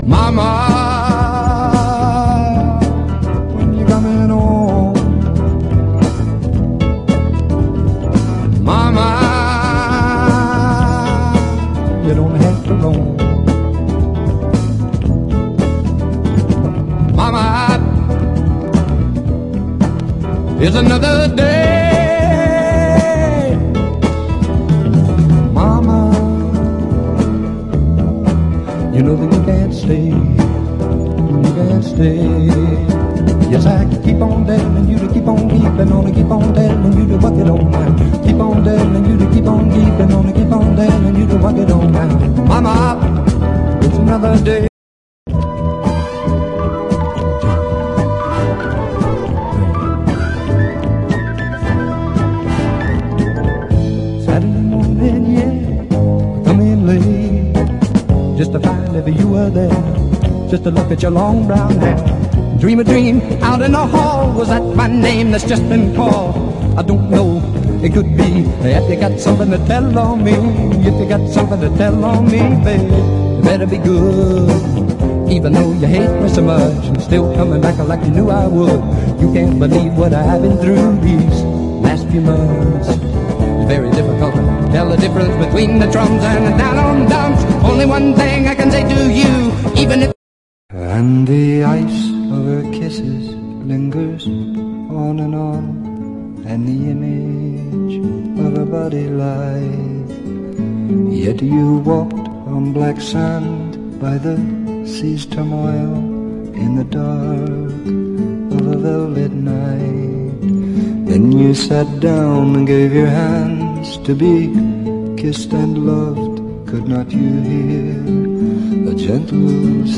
ROCK / 80'S/NEW WAVE. / NEW WAVE / 80'S / POWER POP / PUNK